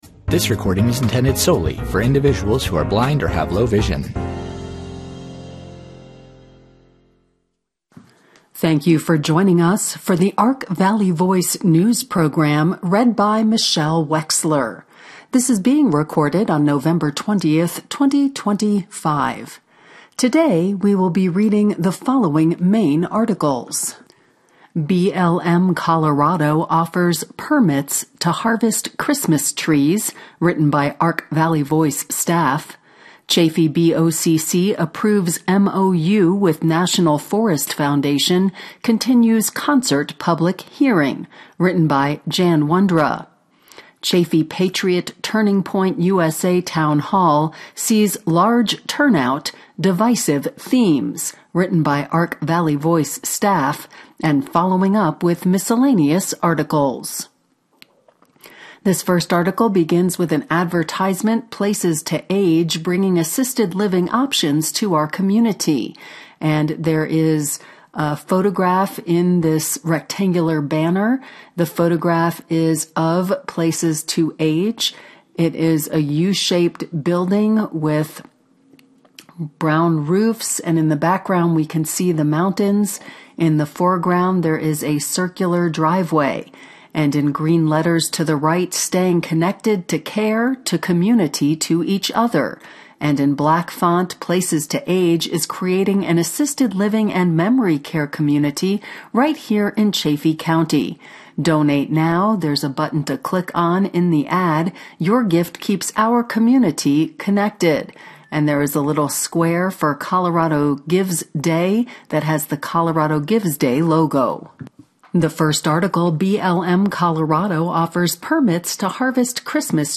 Ark Valley Voice Newspaper in audio, weekly.